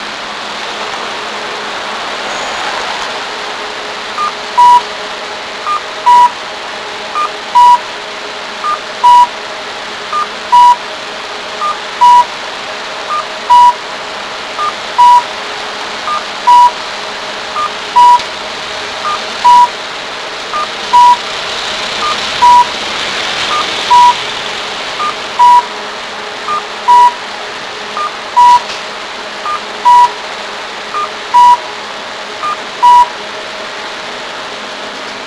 続いてカッコーです。
どちらもスピーカーが歩行者灯器に内蔵されていたために録りやすかったです。
音質はよく聴くような感じですが、カッコーが「ヵッコー」という感じにコーが強調されて聴こえます。